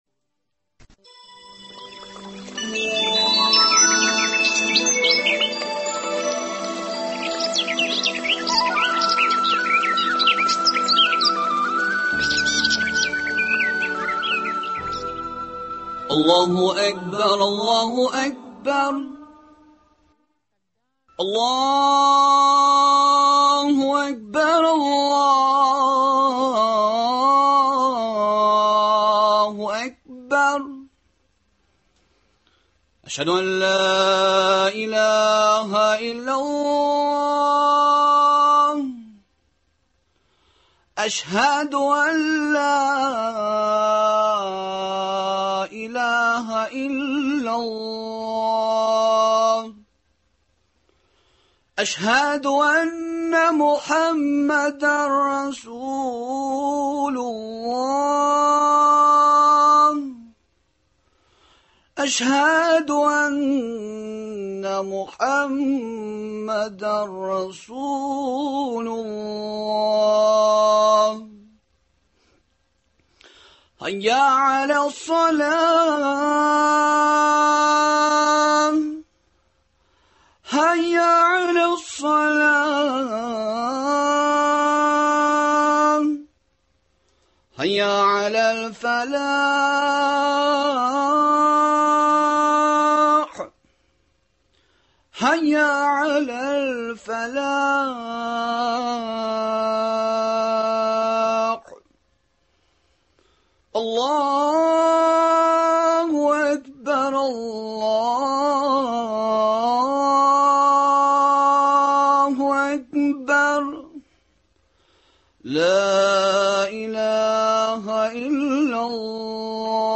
Бу һәм башка сорауларга җавапны студиядә "Гаилә"